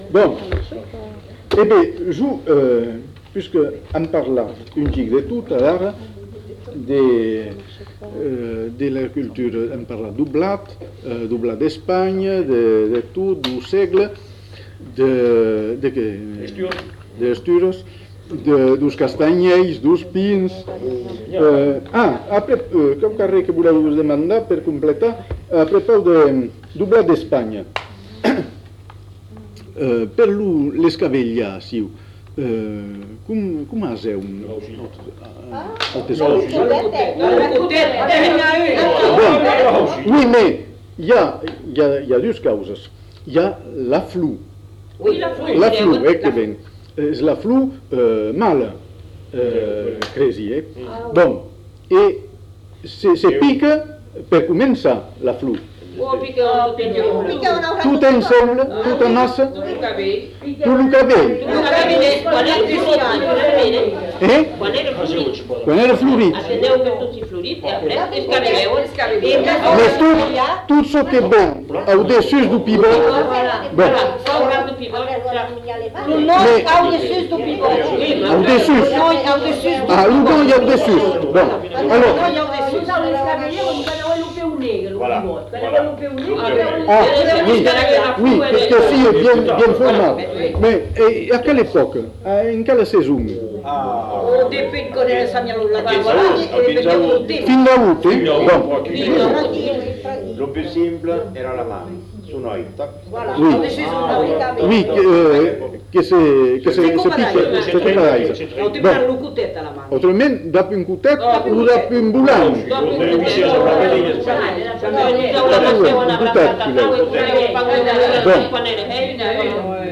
Lieu : Bazas
Genre : témoignage thématique
Notes consultables : Plusieurs informateurs ne sont pas identifiés.